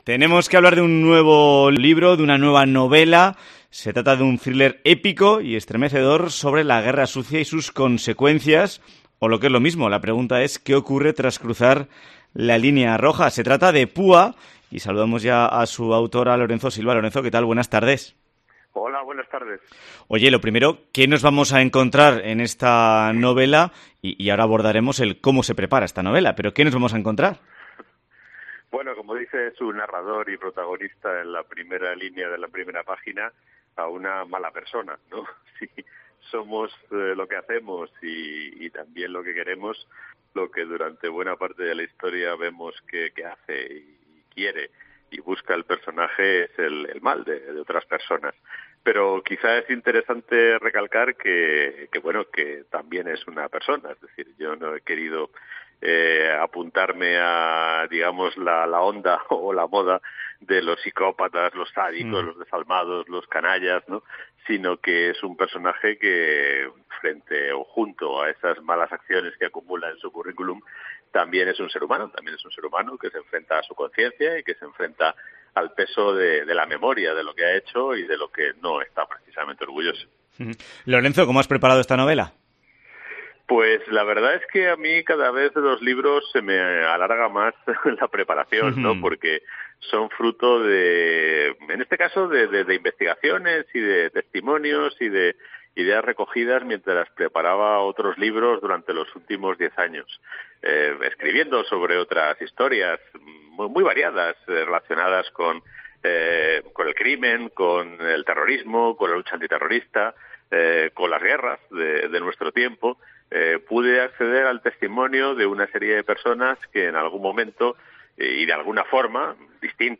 Entrevista a Lorenzo Silva en COPE Navarra